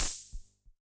RDM_TapeA_SR88-OpHat.wav